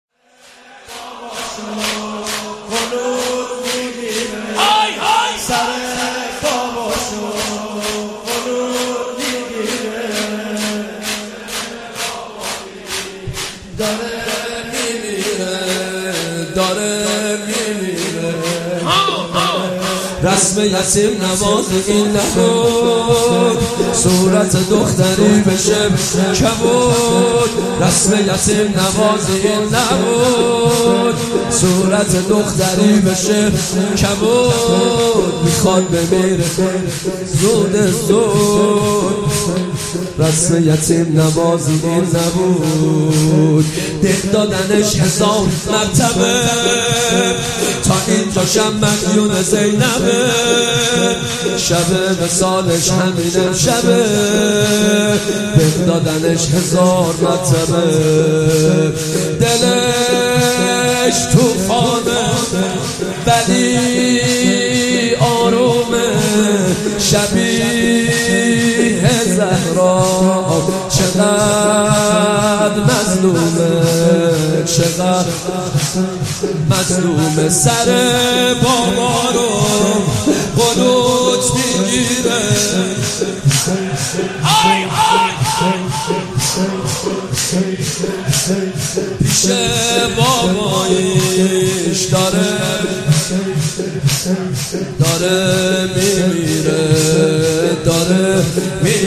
مداحی
شور